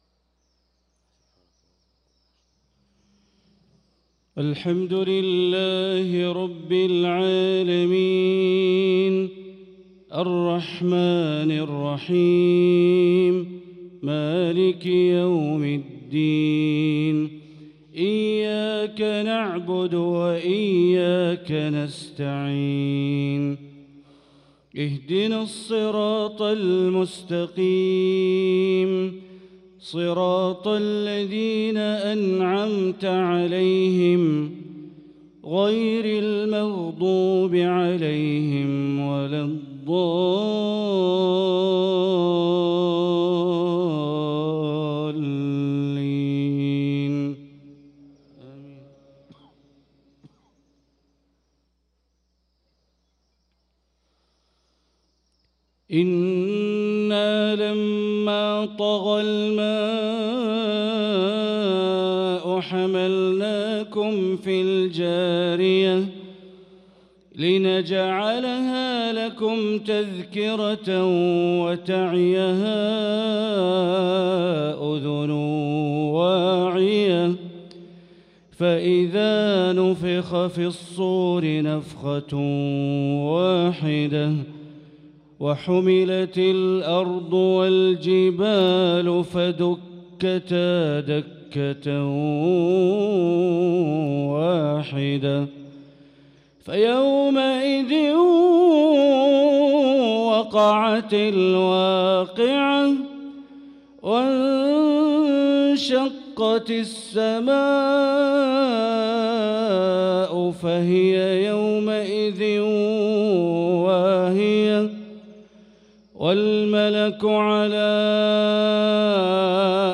صلاة العشاء للقارئ بندر بليلة 25 رجب 1445 هـ